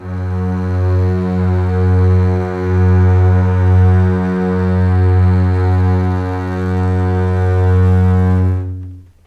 F#2 LEG MF L.wav